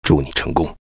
文件 文件历史 文件用途 全域文件用途 Bk2_fw_04.ogg （Ogg Vorbis声音文件，长度0.8秒，134 kbps，文件大小：14 KB） 源地址:游戏语音 文件历史 点击某个日期/时间查看对应时刻的文件。